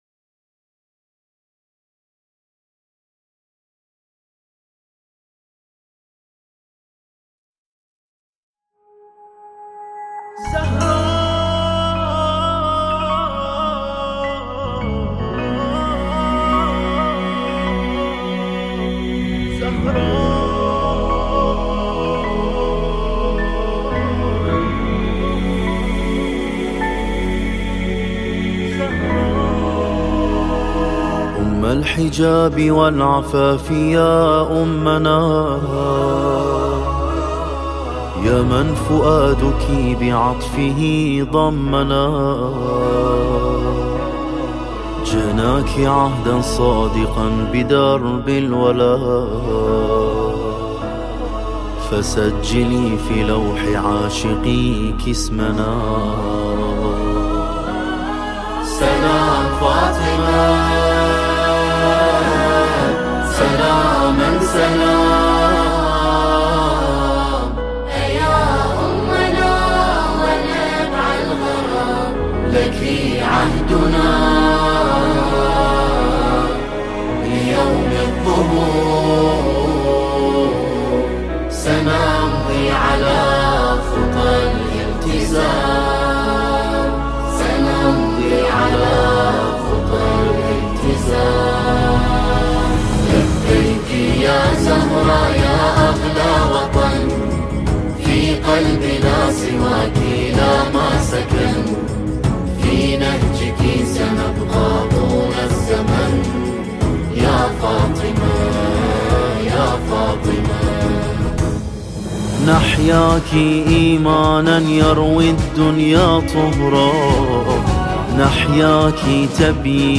نماهنگ عربی زیبای
به مناسبت جشن ولادت حضرت فاطمه سلام الله علیها